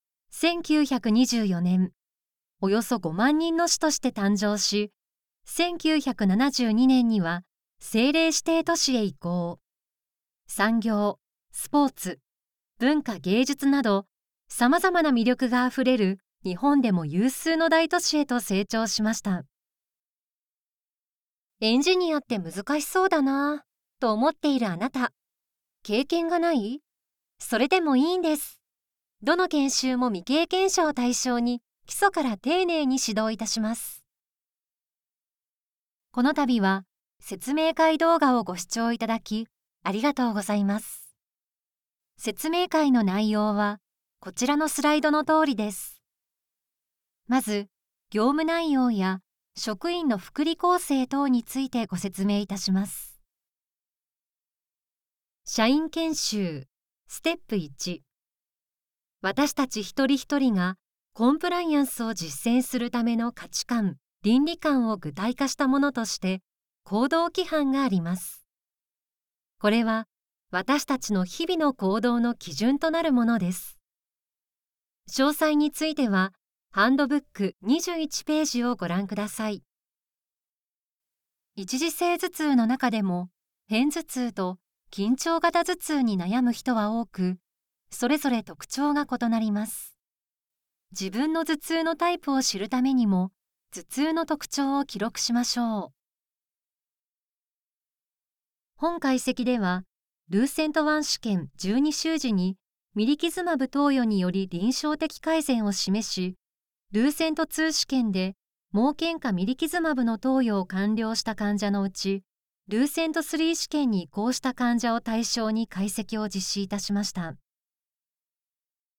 • 【VP】①行政　②リクルート　③セミナー　④社員研修　⑤医療１　⑥医療２